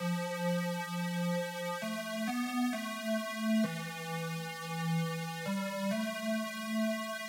碧波大鼓
标签： 132 bpm House Loops Drum Loops 1.22 MB wav Key : Unknown
声道立体声